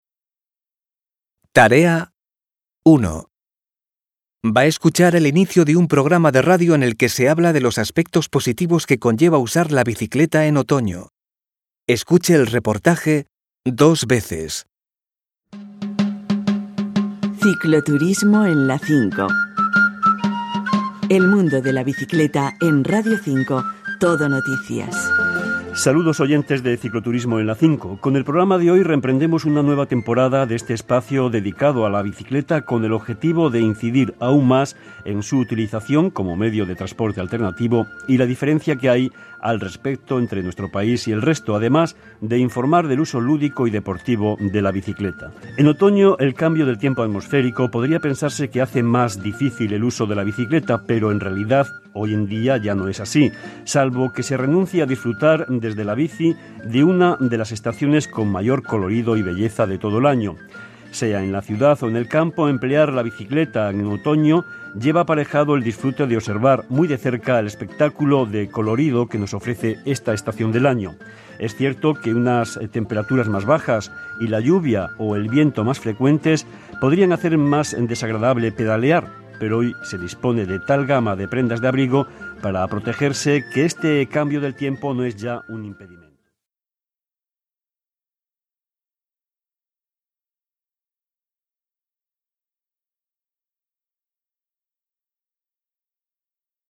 Escuche el inicio de un programa de radio en el que se habla de los aspectos positivos que conlleva usar la bicicleta en otoño.